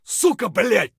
gun_jam_4.ogg